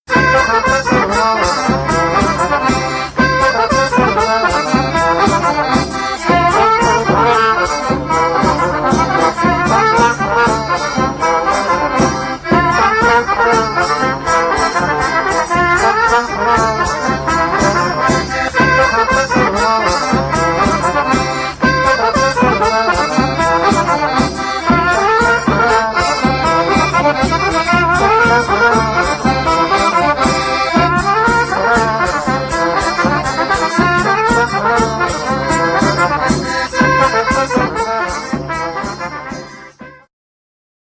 klarnet
puzon wentylowy
harmonia
baraban